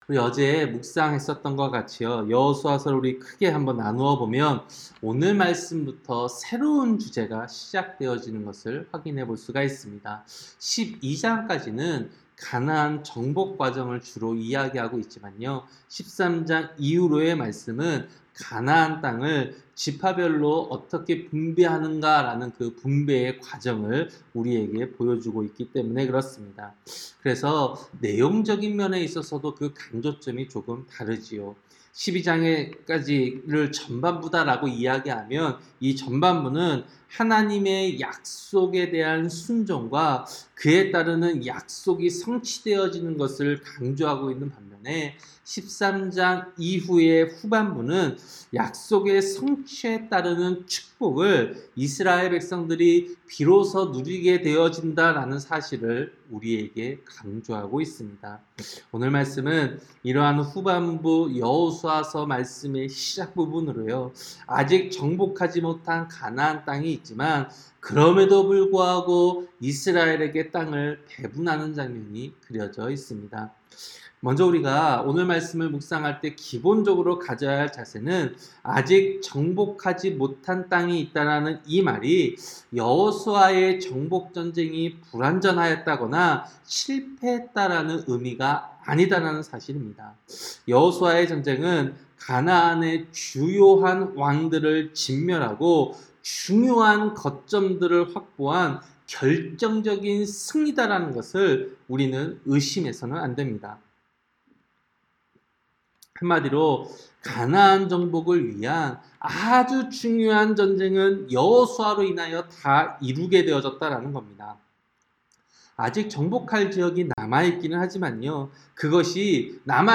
새벽기도-여호수아 13장